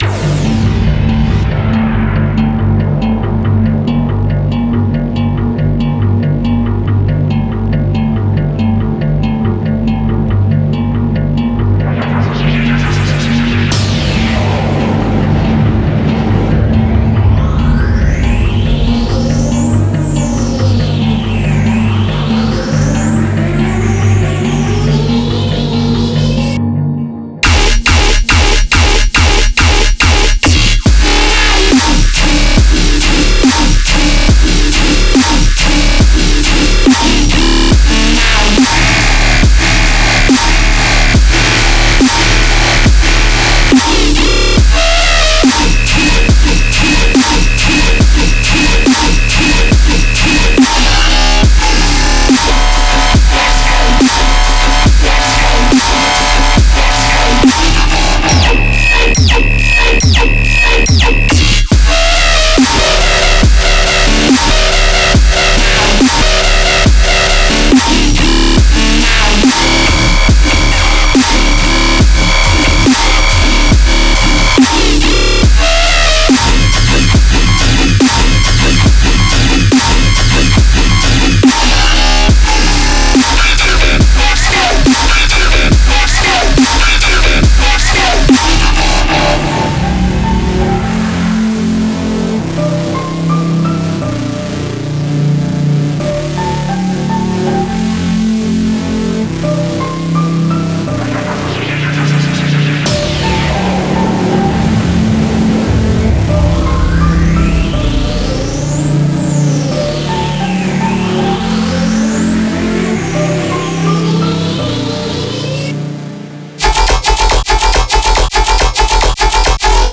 Genre:EDM
デモサウンドはコチラ↓